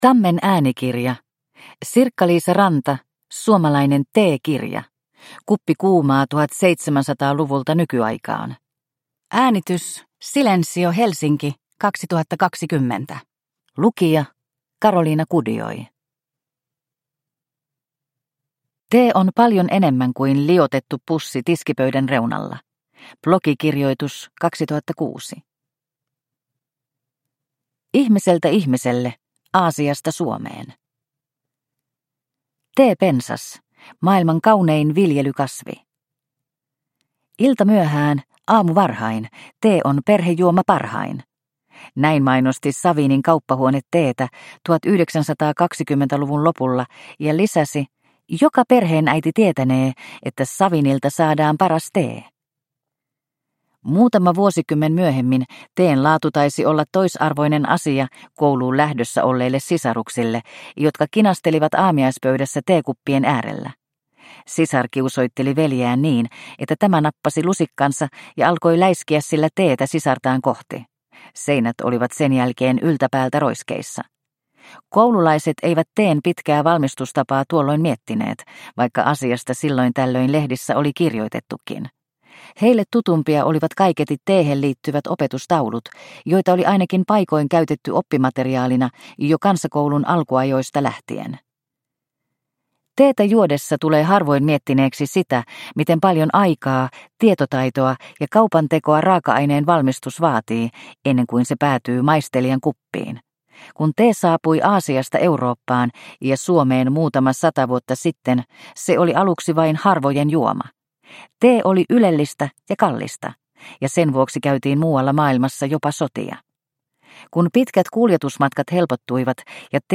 Suomalainen teekirja – Ljudbok – Laddas ner